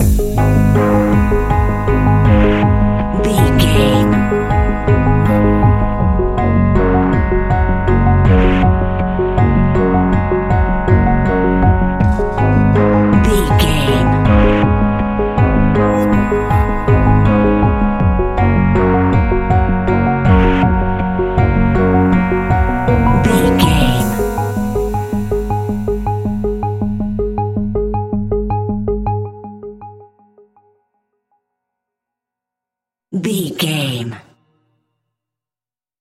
Aeolian/Minor
G#
Slow
ominous
dark
eerie
piano
synthesiser
drums
horror music